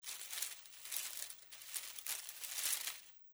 在树叶中行走－高频－YS070525.mp3
通用动作/01人物/01移动状态/06落叶地面/在树叶中行走－高频－YS070525.mp3
• 声道 立體聲 (2ch)